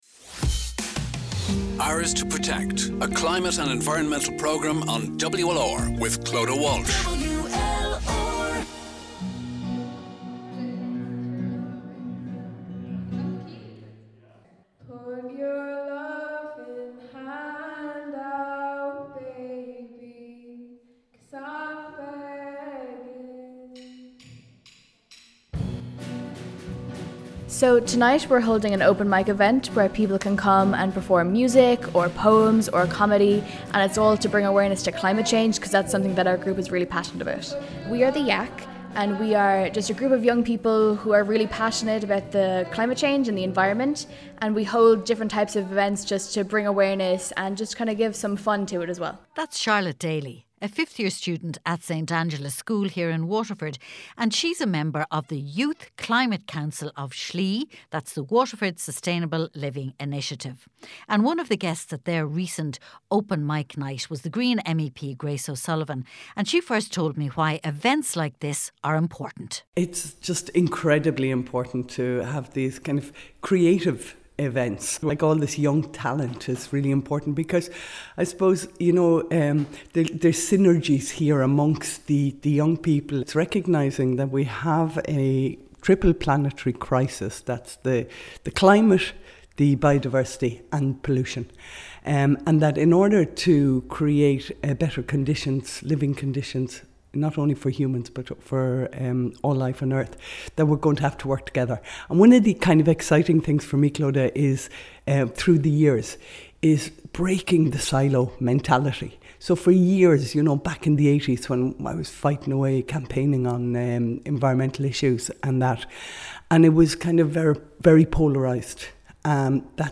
held an open mic night in St Patricks Gateway recently where they used the Arts to promote their climate change message.